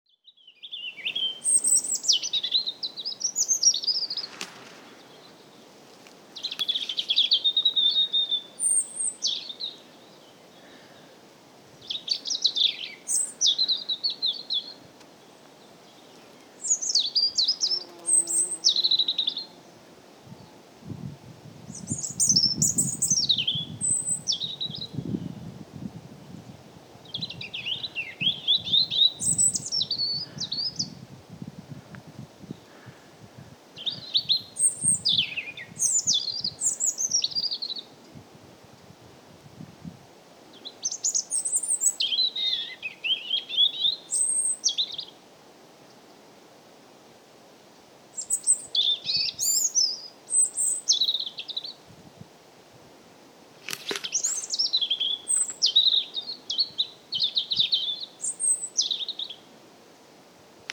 Птицы -> Дроздовые ->
зарянка, Erithacus rubecula
СтатусПоёт